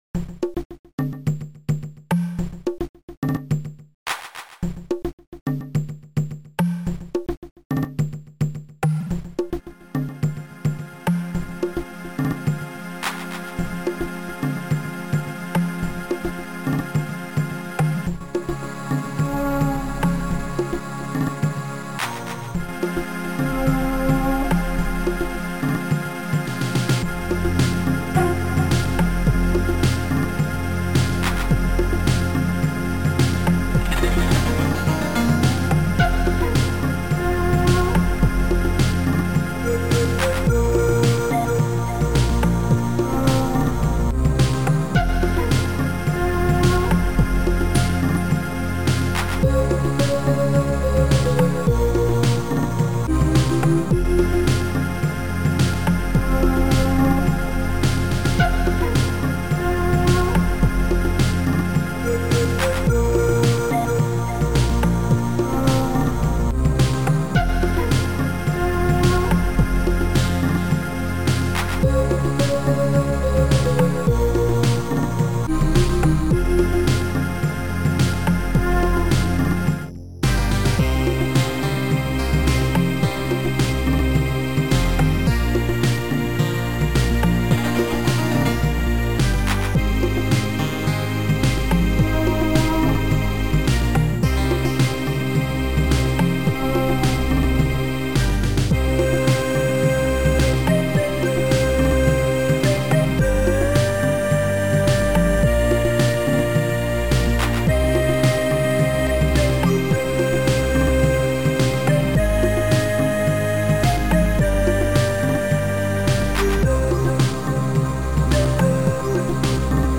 Sound Format: Noisetracker/Protracker
Sound Style: Ambient